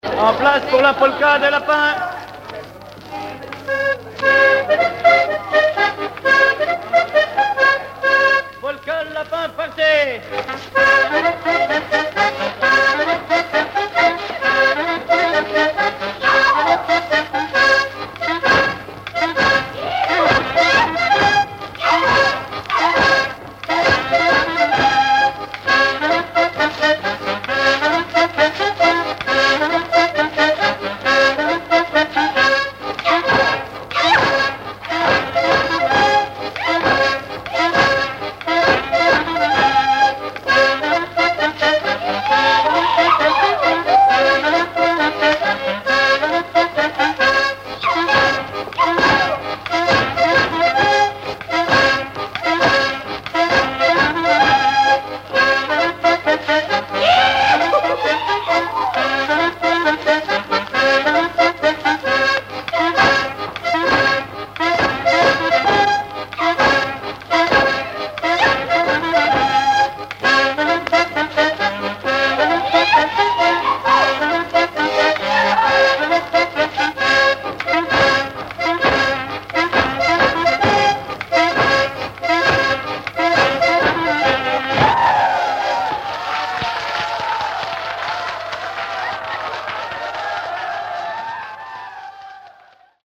danse : polka lapin
Pièce musicale inédite